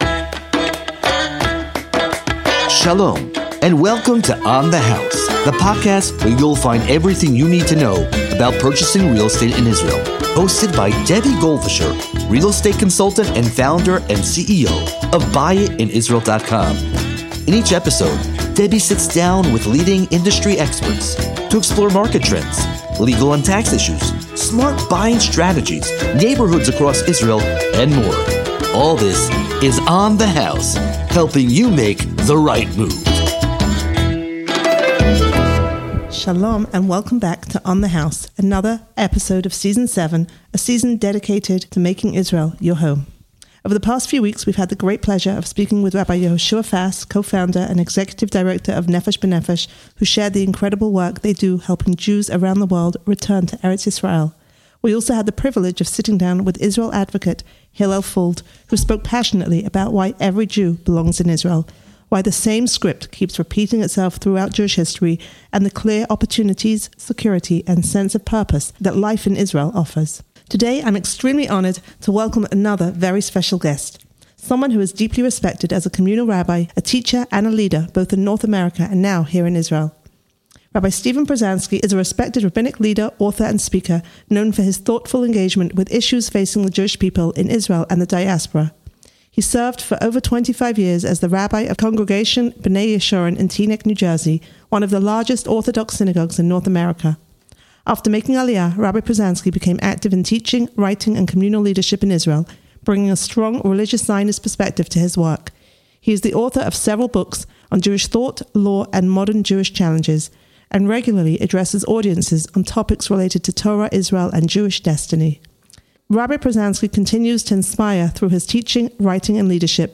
In this moving and thought-provoking conversation